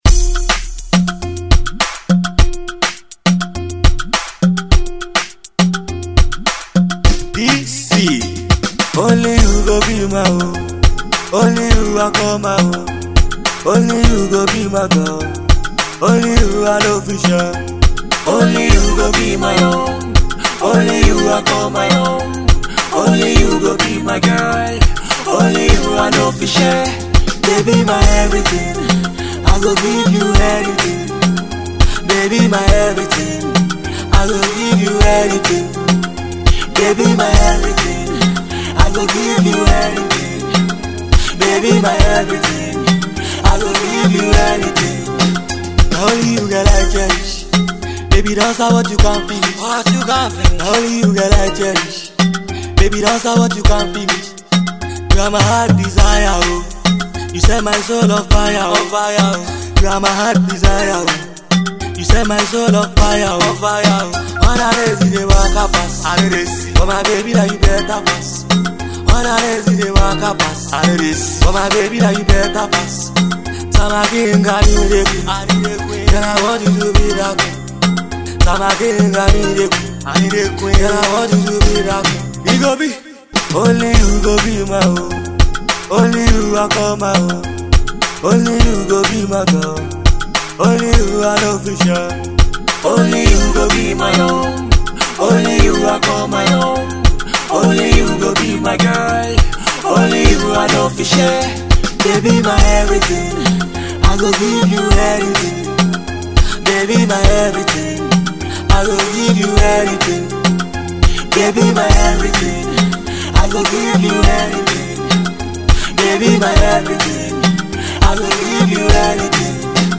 is a love song.